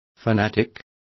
Complete with pronunciation of the translation of fanatic.